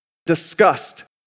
Can you differentiate the words discussed and disgust as produced by native speakers in natural sentences?
discussed or disgust? (USA)